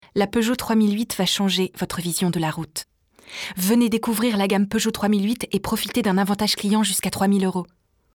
EXTRAITS VOIX
PUBLICITES /SLOGAN /BANDE ANNONCE